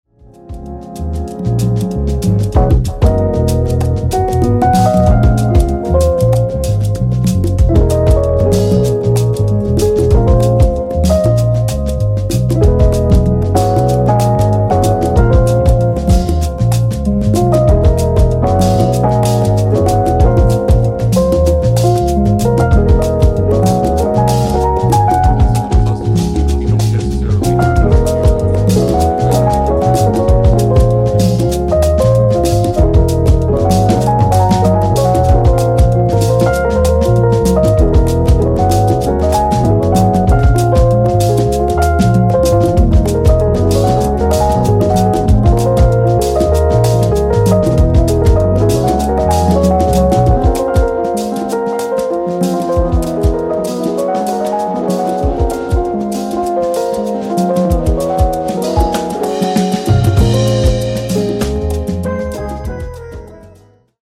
hip hop influenced jazz